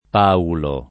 p#ulo] (oltre alla forma Polo [p0lo], schietta continuaz. del lat.